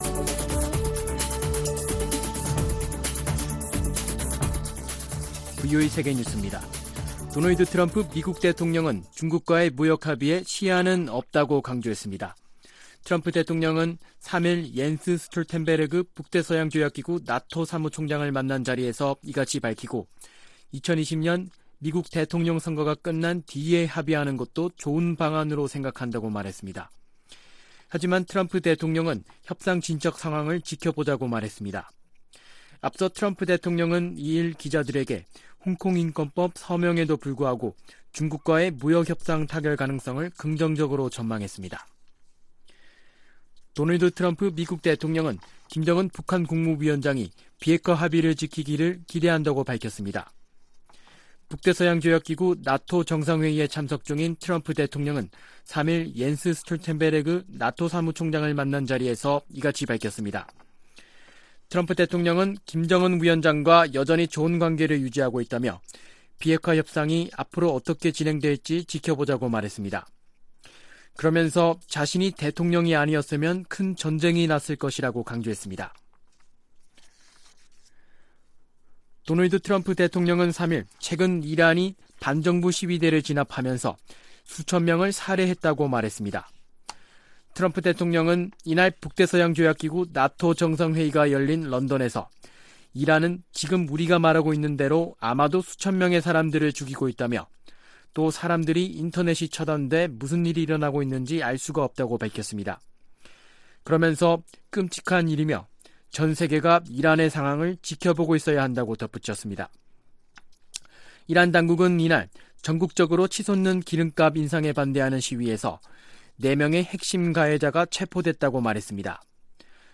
VOA 한국어 아침 뉴스 프로그램 '워싱턴 뉴스 광장' 2018년 12월 4일 방송입니다. 도널드 트럼프 미국 대통령이 김정은 북한 국무위원장에게 비핵화 합의를 촉구하면서, 필요하다면 무력을 사용할 수도 있다고 밝혔습니다. 일본과 인도가 최근 첫 외교-국방 장관 회담을 열고 상호 안보 협력을 한층 강화하기로 했습니다.